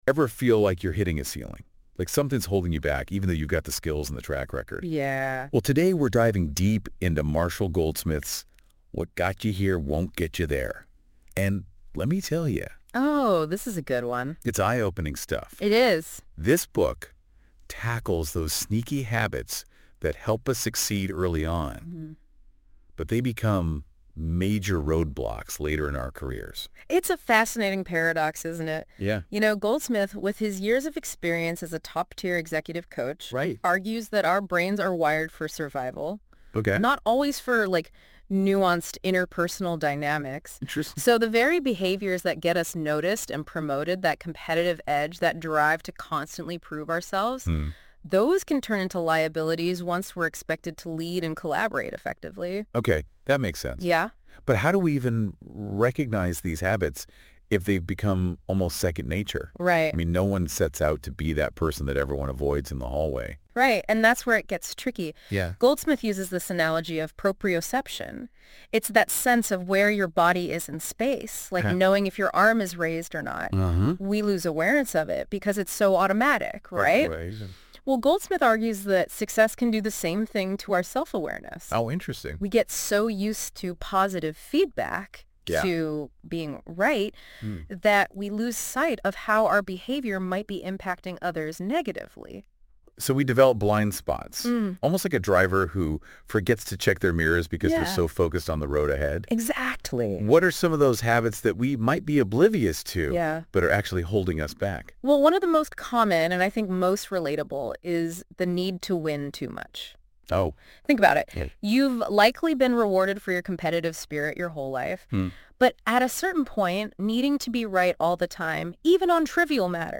Audio Overview AI generated podcast for those keen to explore the topic in more detail.